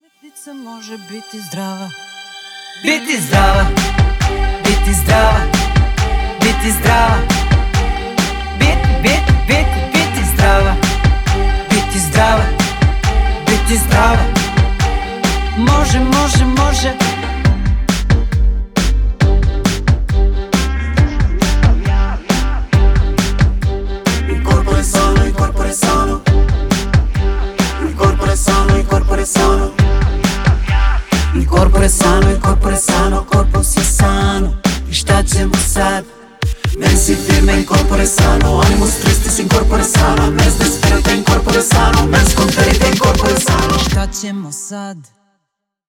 • Качество: 320, Stereo
поп
спокойные
необычные